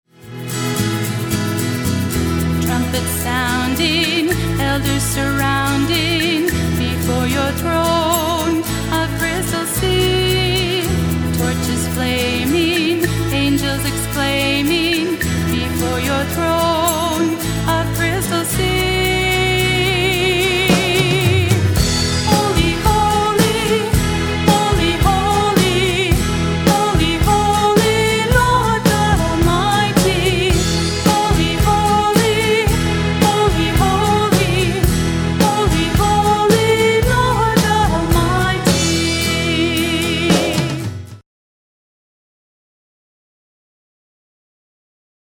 The heart of my music ministry is praise and worship!